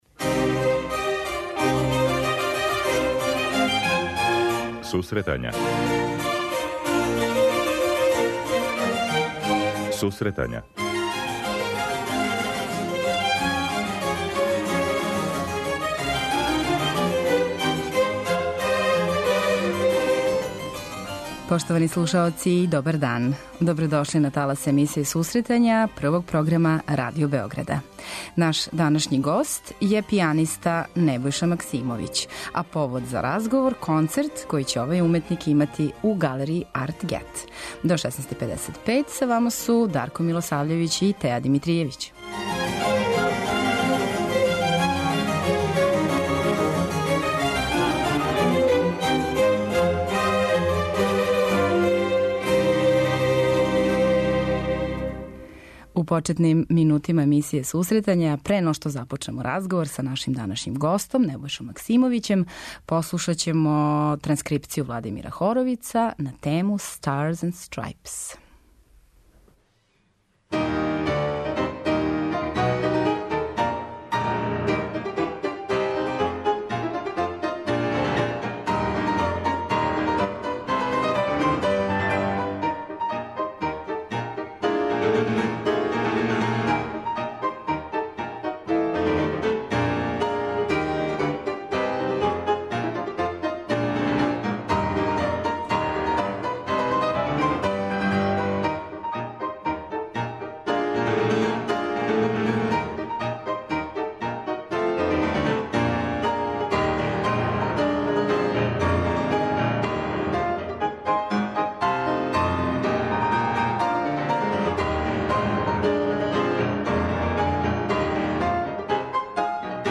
преузми : 26.03 MB Сусретања Autor: Музичка редакција Емисија за оне који воле уметничку музику.